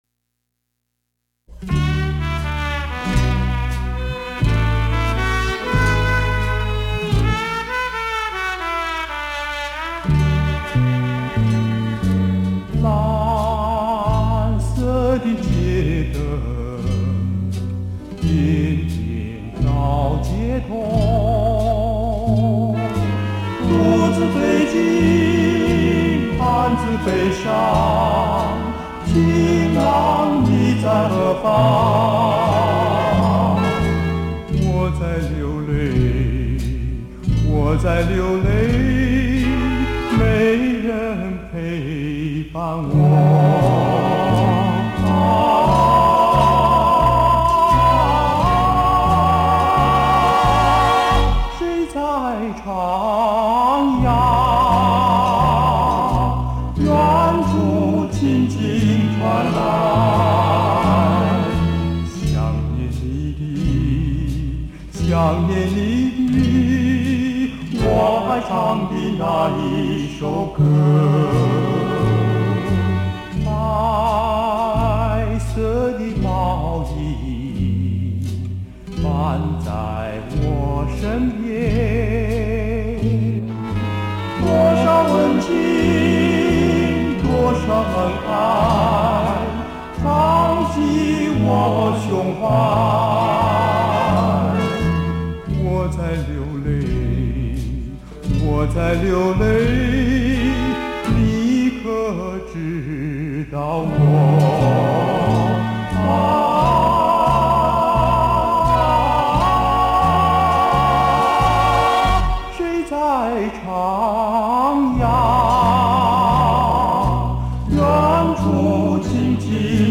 嗓音渾厚嘹亮的他們，令不少老歌迷印象深刻。